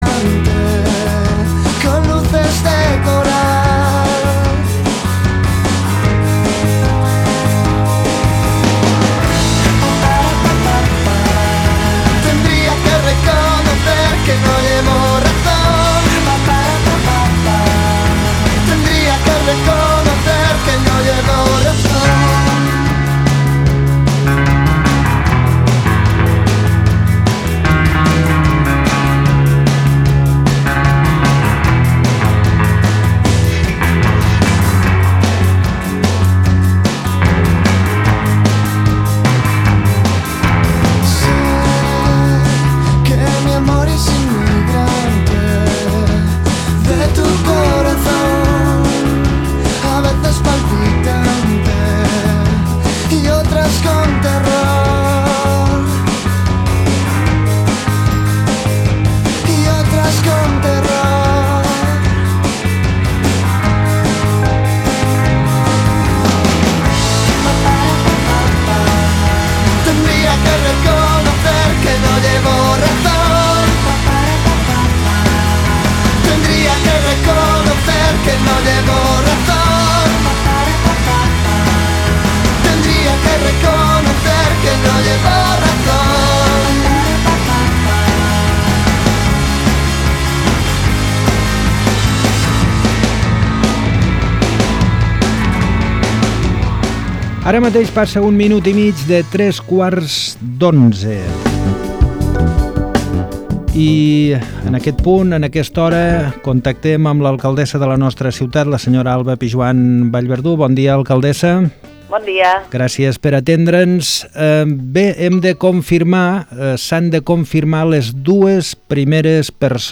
ÀUDIO Entrevista amb l'alcaldessa de Tàrrega després de la confirmació dels dos primers casos de Coronavirus al municipi.
Escolta l'entrevista que em fet a l'alcaldessa de Tàrrega Alba Pijuan Vallverdú tan bon punt s'ha conegut que hi ha els dos primers casos de coronavirus al municipi.
alcaldessa.mp3